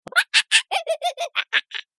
دانلود صدای میمون 11 از ساعد نیوز با لینک مستقیم و کیفیت بالا
جلوه های صوتی